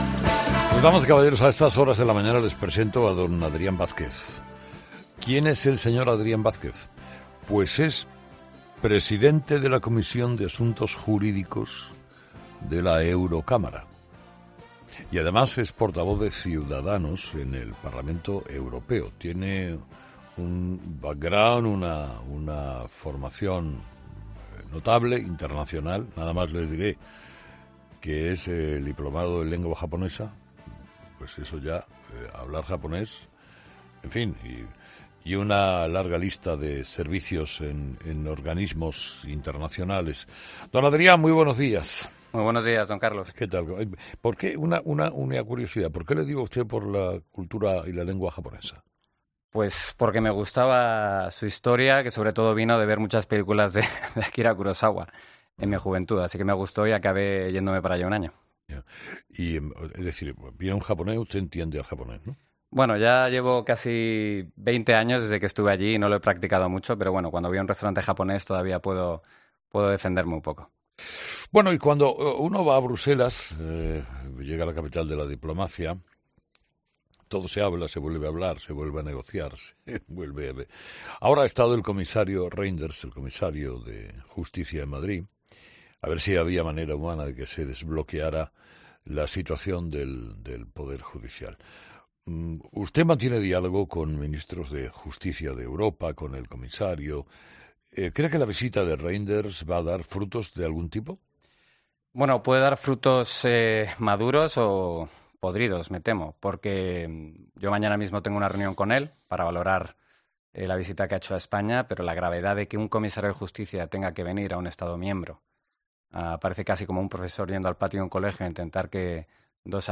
AUDIO: Entrevista a Adrián Vázquez, eurodiputado en el Parlamento Europeo por Ciudadanos